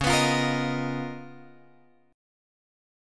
C# 13th